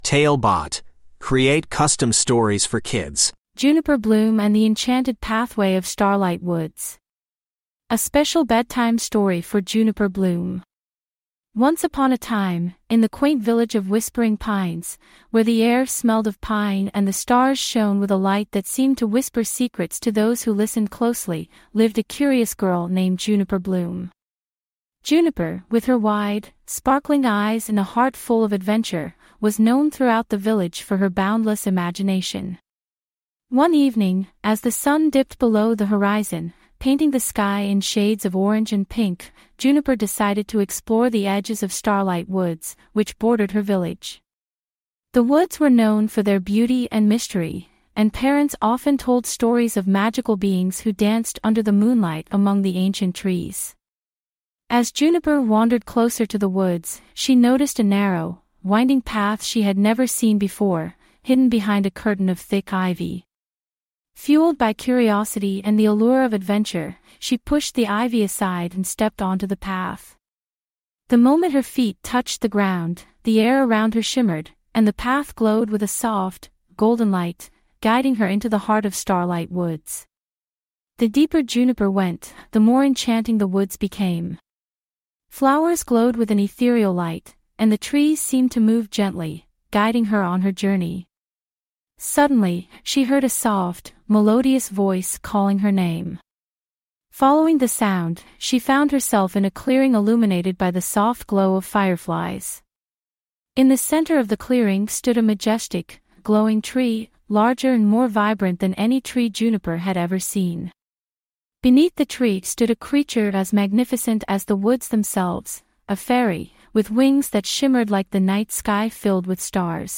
TaleBot AI Storyteller